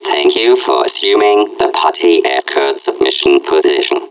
Like most Aperture robots, the Party Escort Bot possesses a mechanical tone of voice.